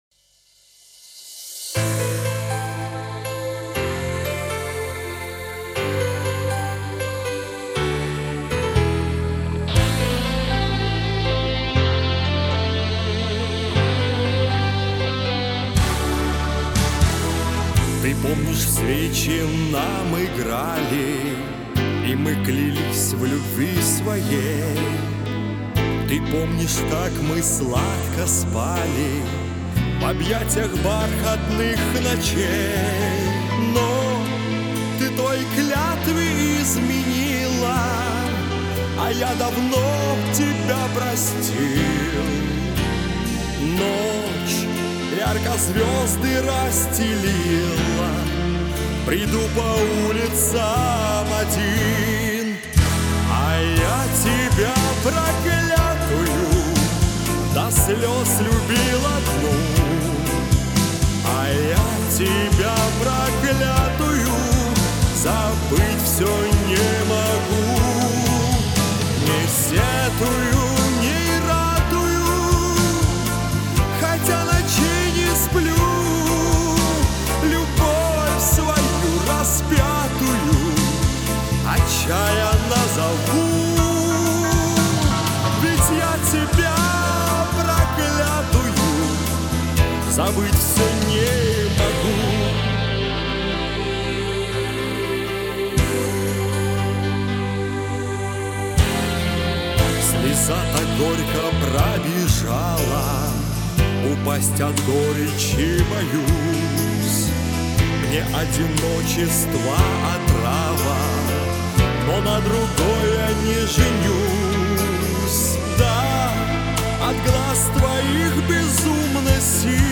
Какие красивые мужественные голоса.....